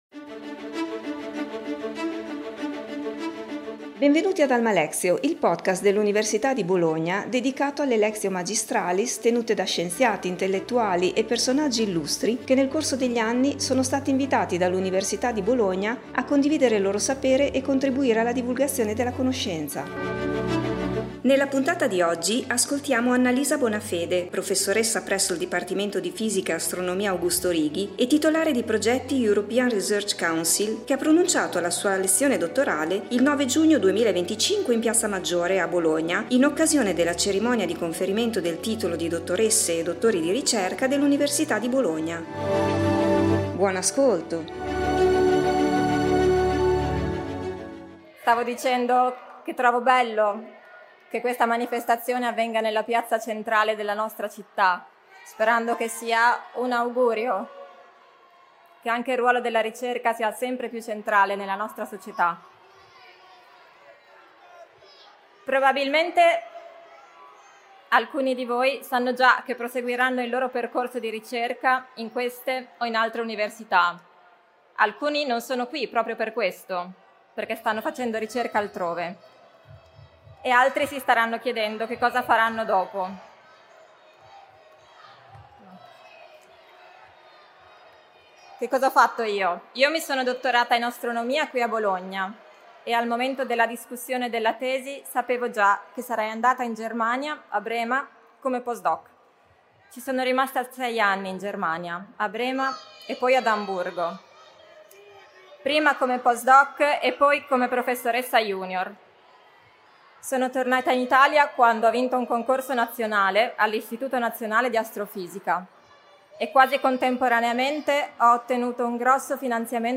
ha pronunciato la sua lectio magistralis il 9 giugno 2025 in piazza Maggiore a Bologna in occasione della Cerimonia di Conferimento del titolo di dottoresse e dottori di ricerca dell’Università di Bologna.